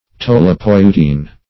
Tolypeutine \Tol`y*peu"tine\, n.
tolypeutine.mp3